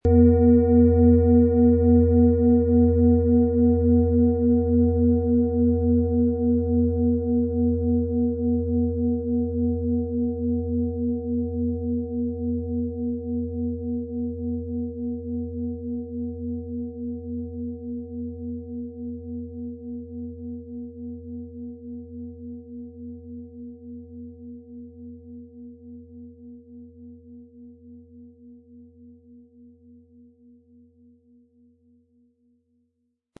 Planetenschale® Befreit sein & Stärke Dein Selbstbewusstsein mit Chiron & Delfin-Ton, Ø 25,7 cm, 1400-1500 Gramm inkl. Klöppel
Nach überlieferter Tradition hergestellte Klangschale mit Chiron.Die Klangschalen lassen wir von kleinen, traditionellen Betrieben fabrizieren.
• Mittlerer Ton: Delfin
Unter dem Artikel-Bild finden Sie den Original-Klang dieser Schale im Audio-Player - Jetzt reinhören.
PlanetentöneChiron & Delfin & Lilith (Höchster Ton)
MaterialBronze